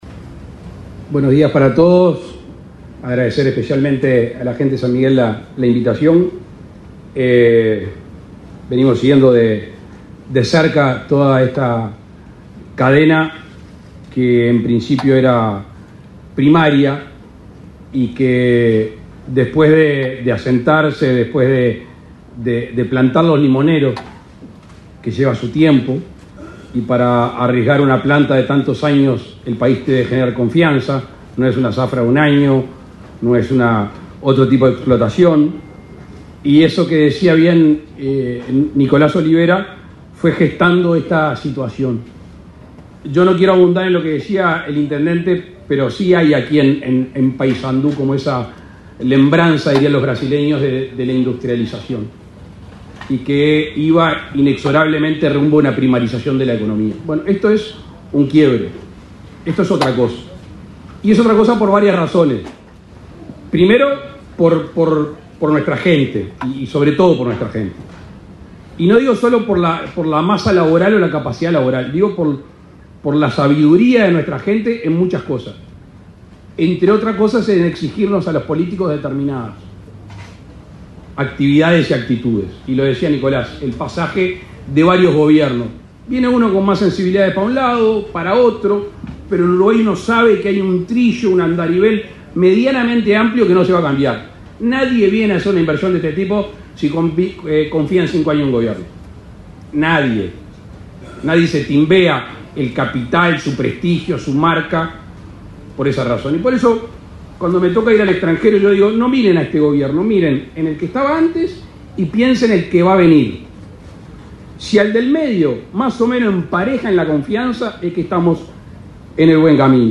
Palabras del presidente de la República, Luis Lacalle Pou
El presidente de la República, Luis Lacalle Pou, participó, este 13 de junio, de la inauguración de la fábrica de procesamiento de limones San Miguel,